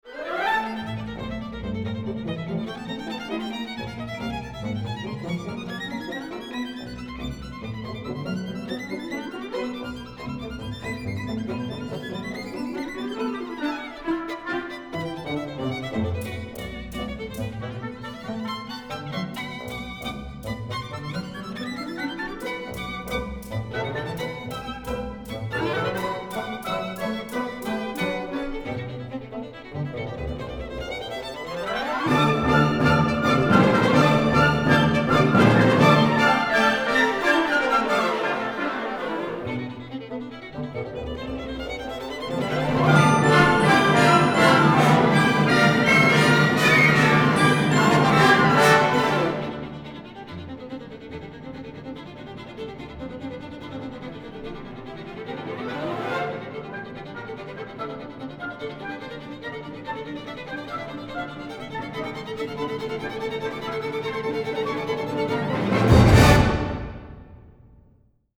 Instrumentation: violin solo, orchestra